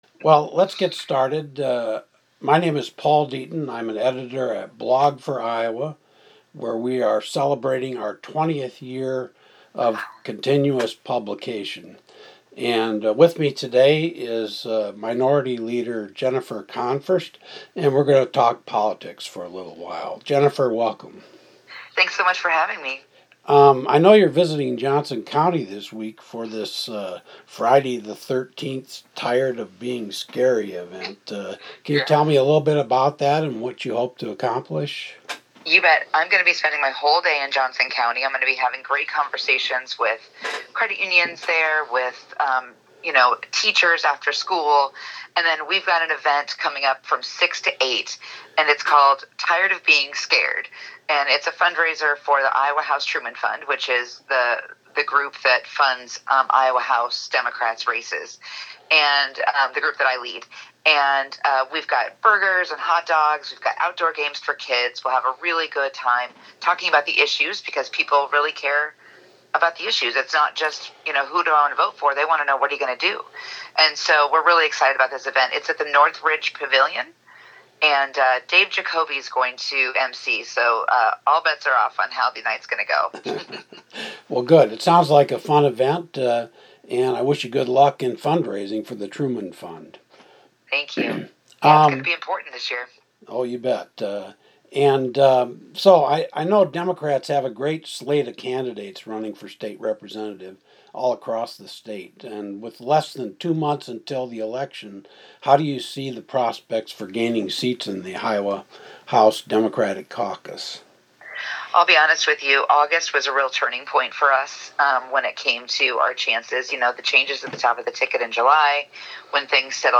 Interview With House Minority Leader Jennifer Konfrst